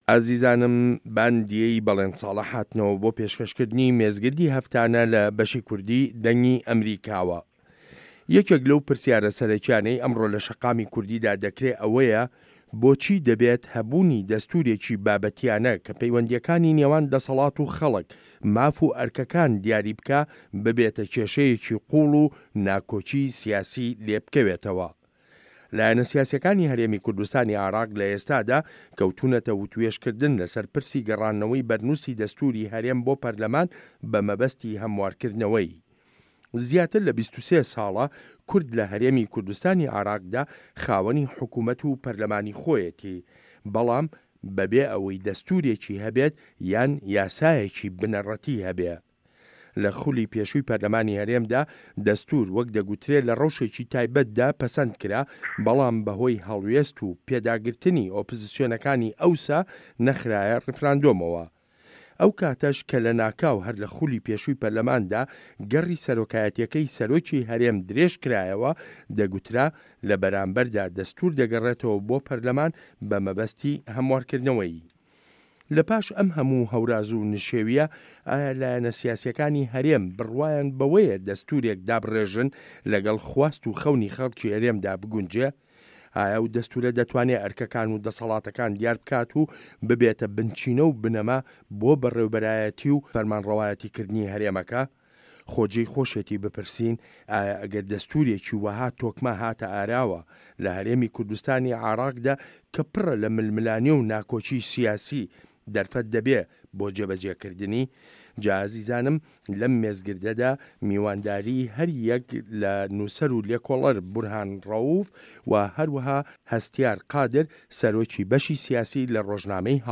مێزگرد: